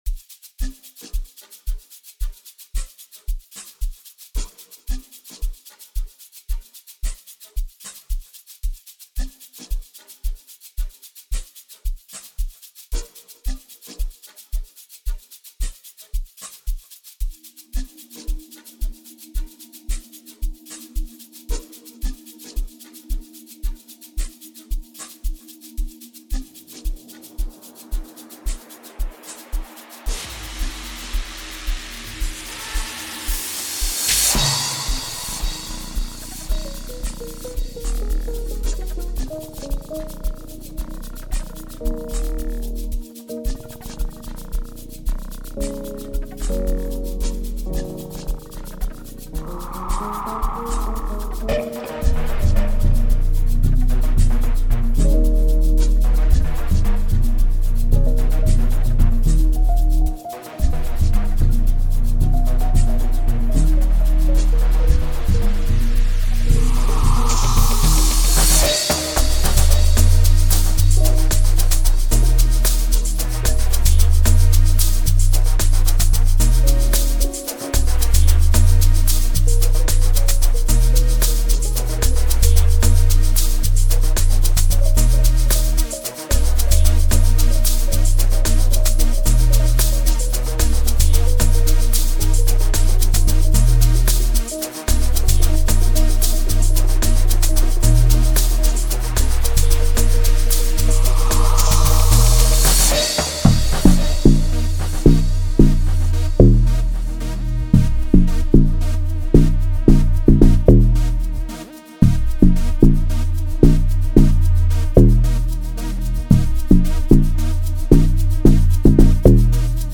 07:00 Genre : Amapiano Size